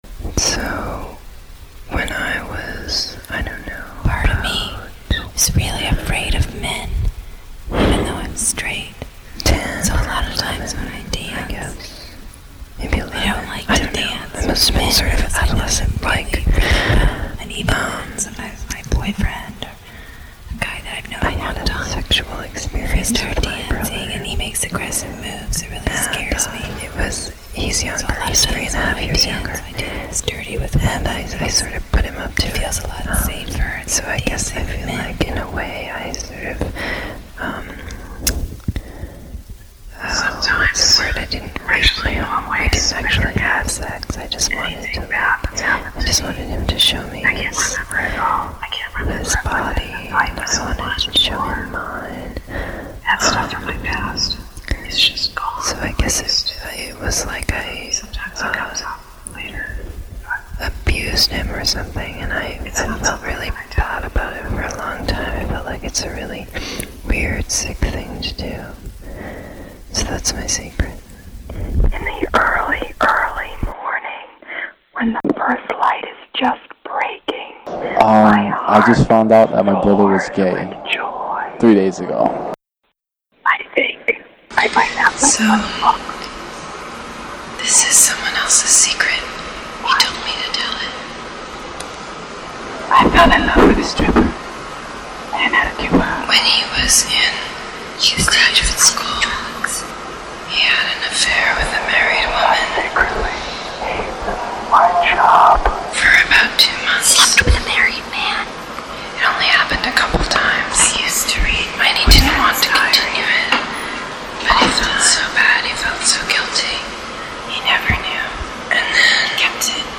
(mix of recordings of strangers whispering their secrets)